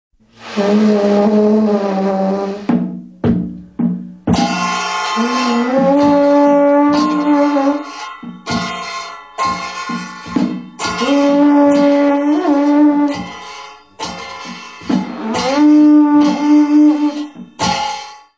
Musique rituelle tibétaine